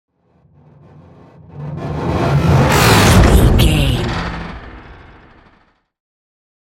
Sci fi vehicle whoosh and hit
Sound Effects
futuristic
tension
woosh to hit